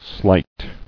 [slight]